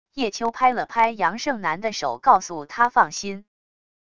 叶秋拍了拍杨胜男的手告诉他放心wav音频生成系统WAV Audio Player